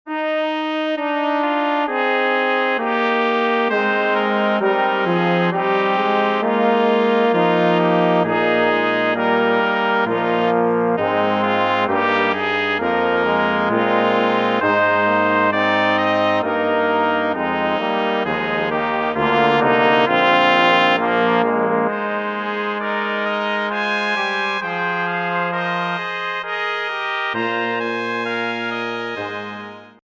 für Blechbläser-Sextett / Blechbläser-Ensemble
Beschreibung:Klassik; Blasmusik